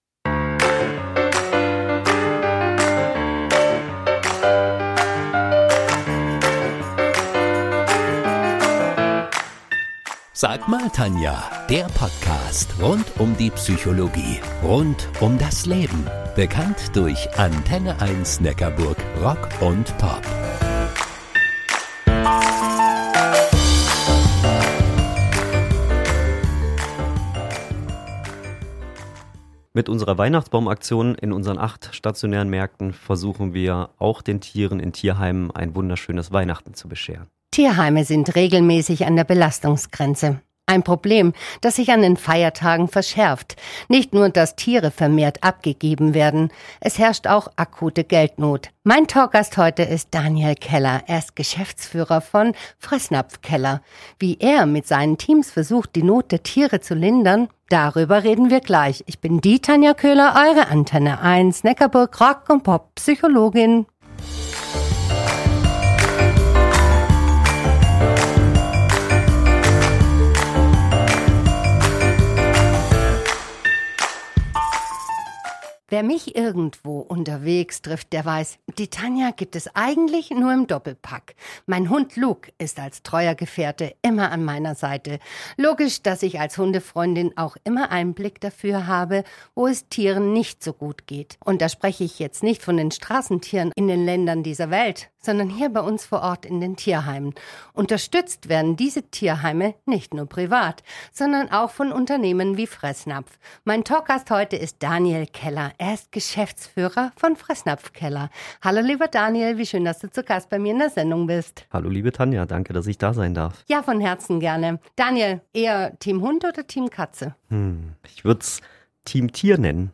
Diese Podcast-Episode ist ein Mitschnitt der Original-Redebeiträge